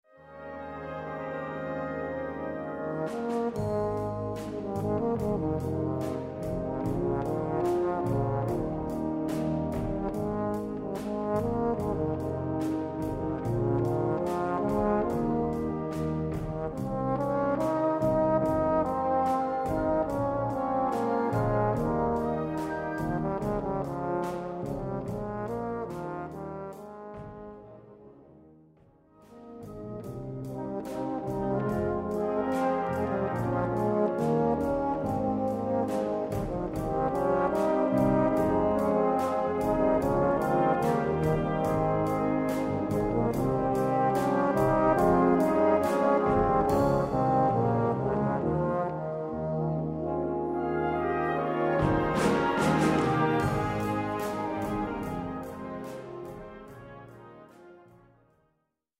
Gattung: Euphonium Duett und Blasorchester
Besetzung: Blasorchester